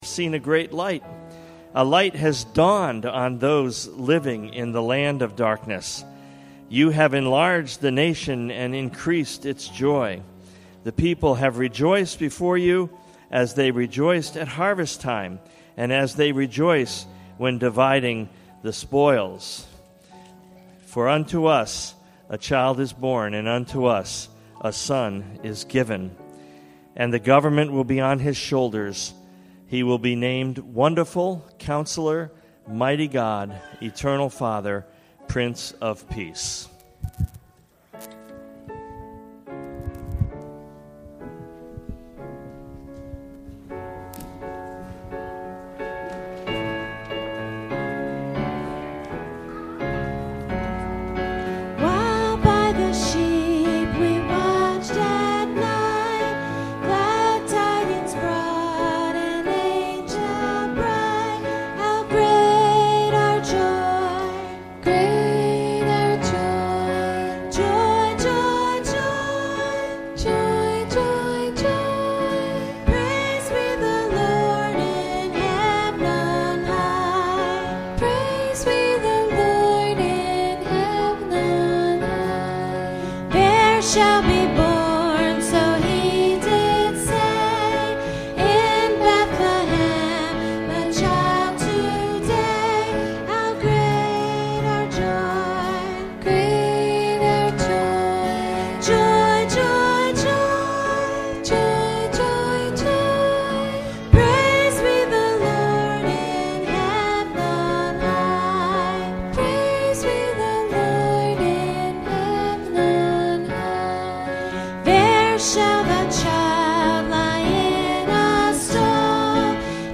Sunday Service – Children’s Christmas Program